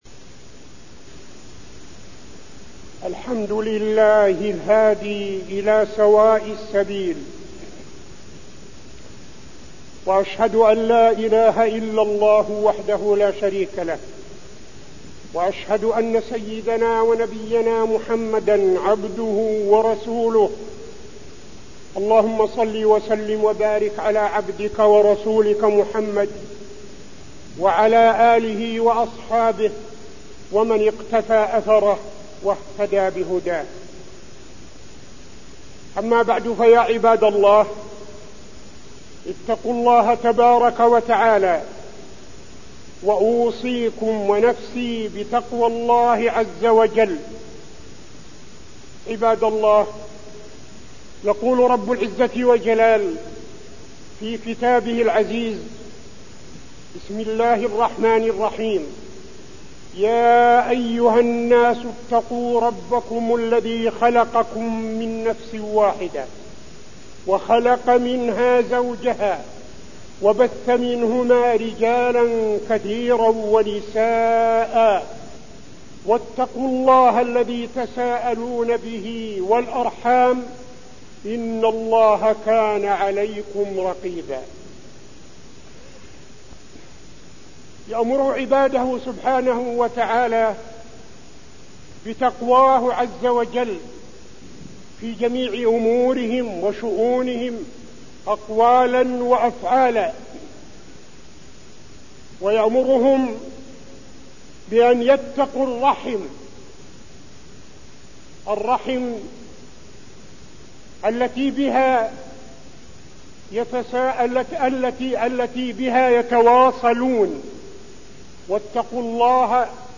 تاريخ النشر ٢ صفر ١٤٠٥ هـ المكان: المسجد النبوي الشيخ: فضيلة الشيخ عبدالعزيز بن صالح فضيلة الشيخ عبدالعزيز بن صالح تقوى الله وصلة الرحم